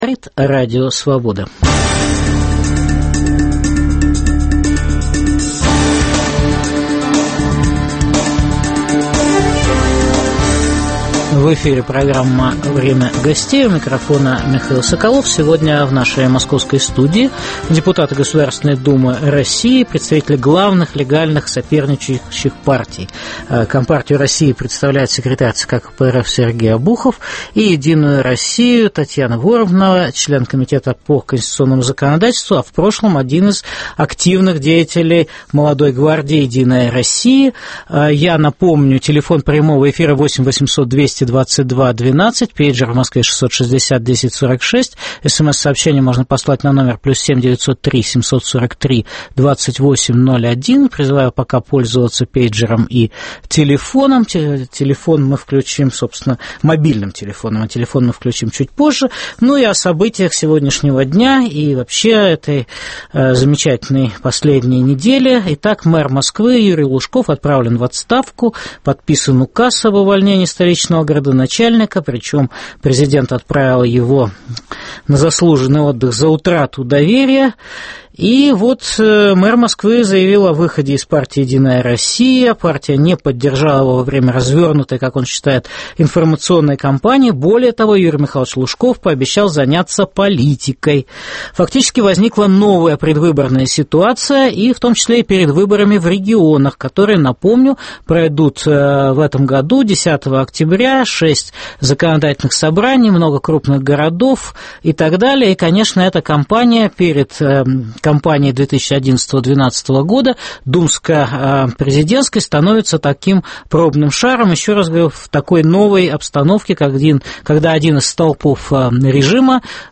Подорвет ли рейтинг "Единой России" отставка Юрия Лужкова? В программе дискутируют депутаты Государственной думы России: член координационного совета "Молодой гвардии "Единой России"", Татьяна Воронова и секретарь ЦК КПРФ Сергей Обухов.